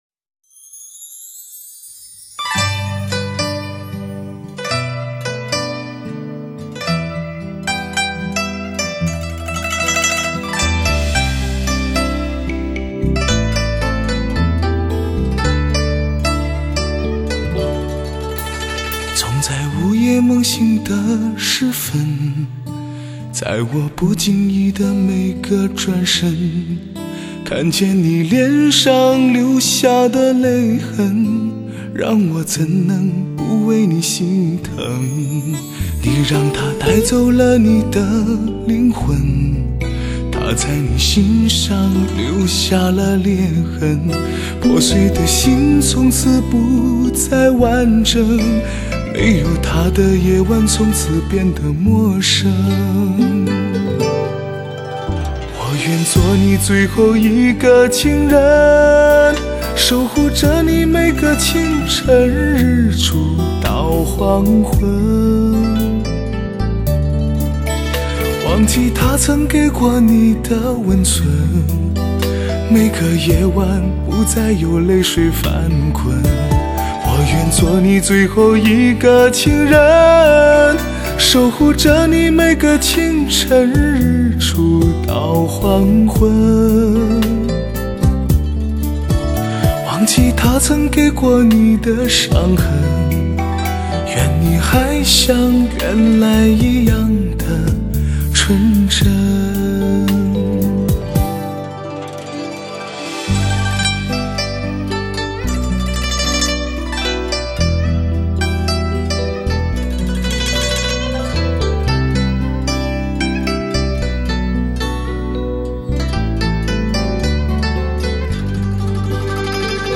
令你难以置信的音效  声色优美  给你绝对超值的感受